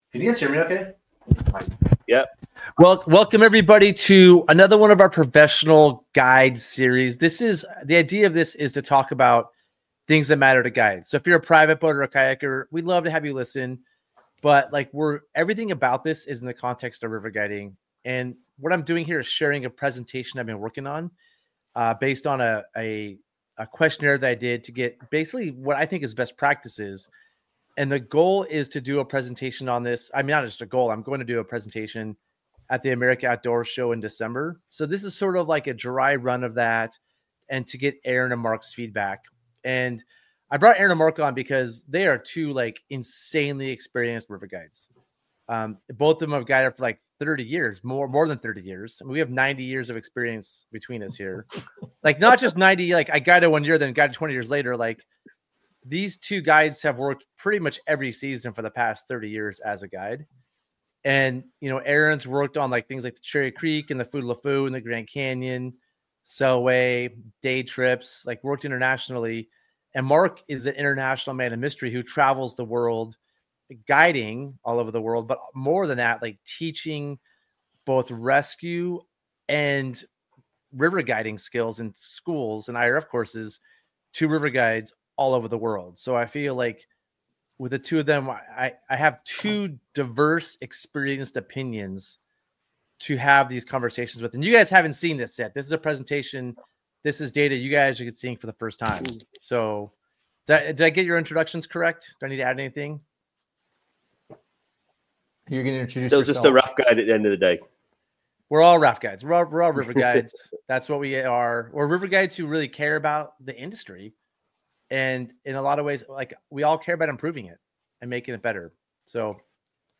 The River Guide Seminar Series is a video series featuring conversations with seasoned river professionals, aimed at continuing education for professional working river guides. From whitewater safety and rigging tips to leadership, river history, and guiding philosophy, we dive into the knowledge that makes great guides even better.